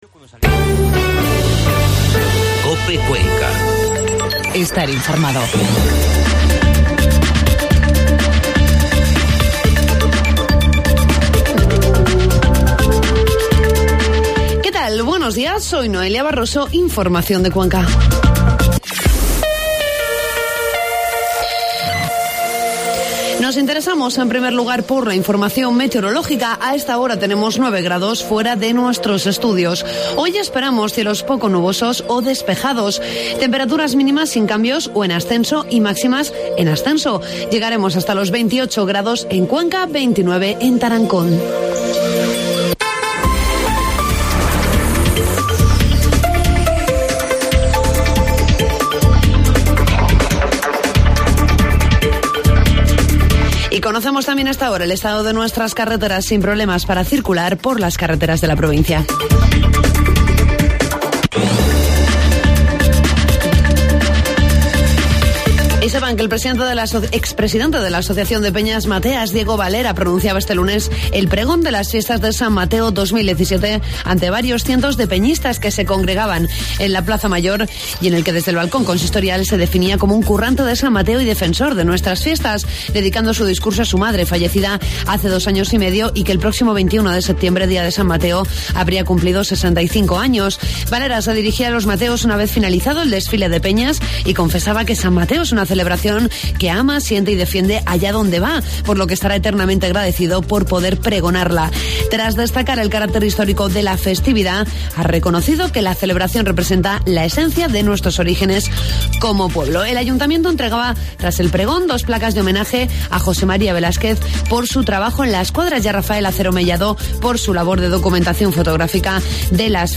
Informativo matinal COPE Cuenca 19 de septiembre